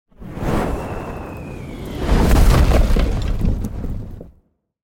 دانلود آهنگ آتش 9 از افکت صوتی طبیعت و محیط
جلوه های صوتی
دانلود صدای آتش 9 از ساعد نیوز با لینک مستقیم و کیفیت بالا